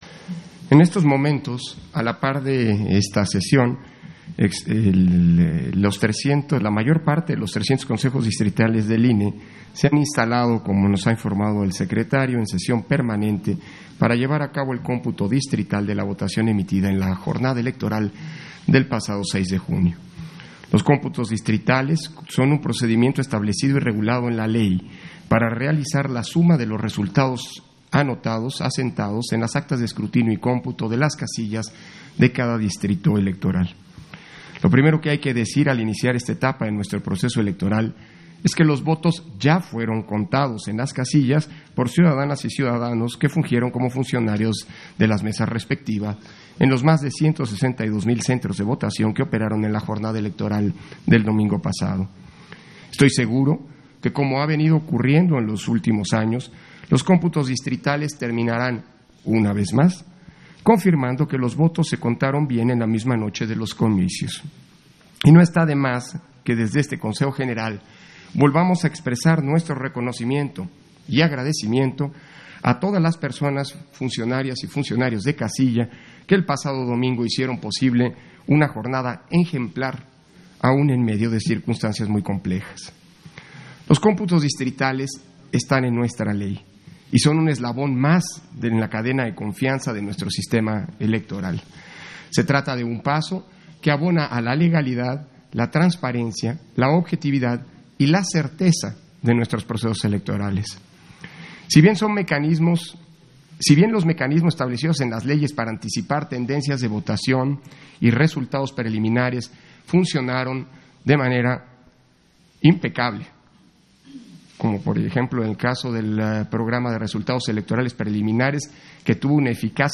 090621_AUDIO_INTERVENCIÓN-CONSEJERO-PDTE.-CÓRDOVA-PUNTO-3-SESIÓN-EXT. - Central Electoral